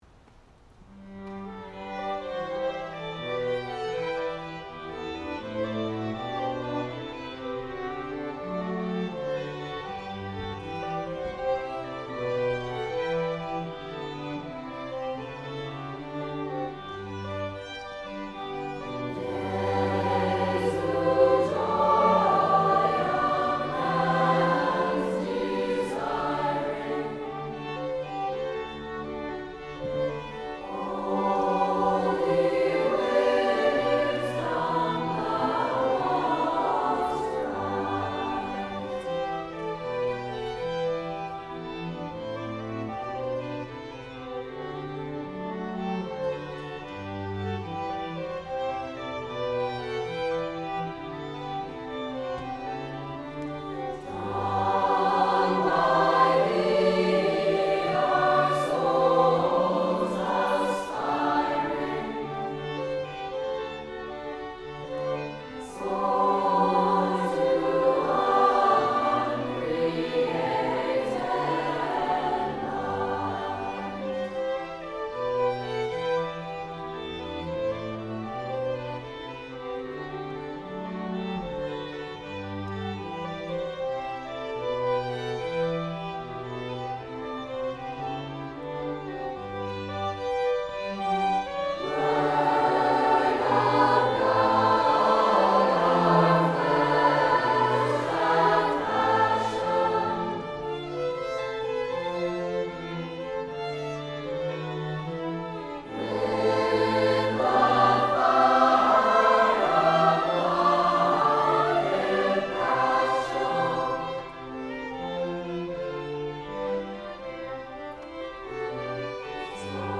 Chamber, Choral & Orchestral Music
Chorus